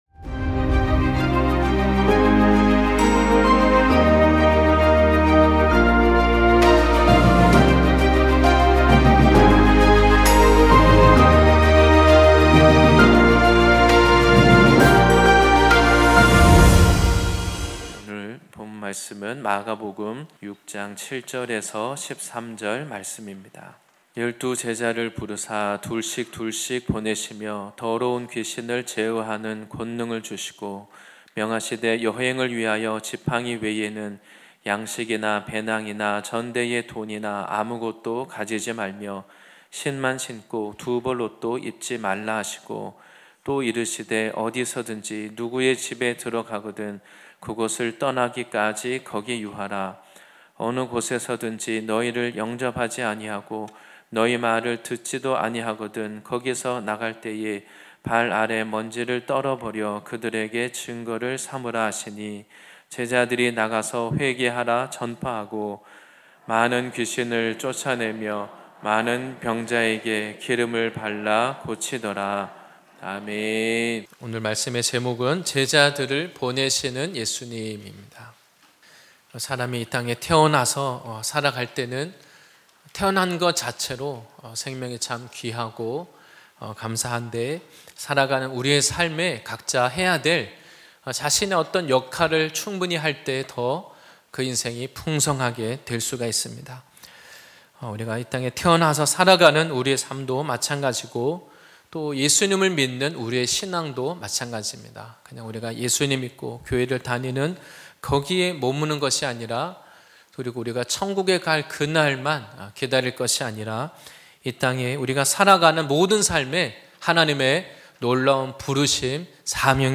2025년10월26일 주일예배말씀